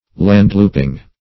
Landlouping \Land"loup`ing\